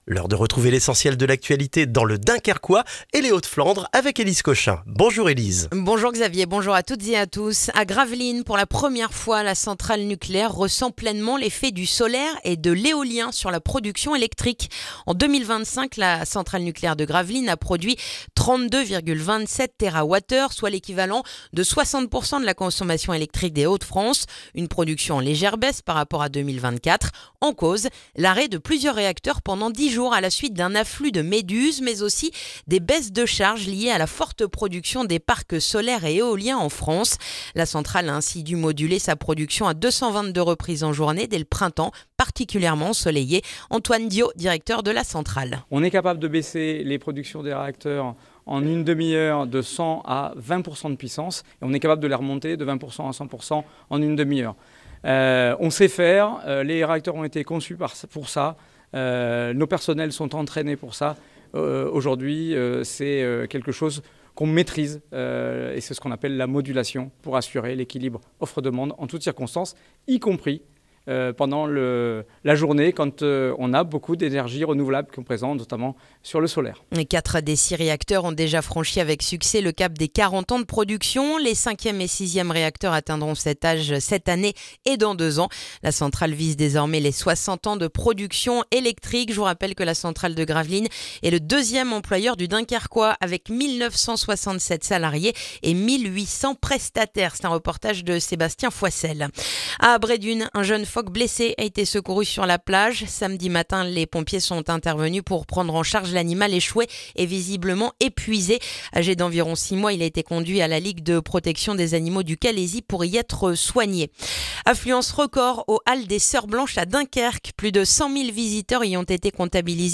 Le journal du lundi 9 février dans le dunkerquois